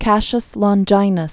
(kăshəs lŏn-jīnəs), Gaius Died 42 BC.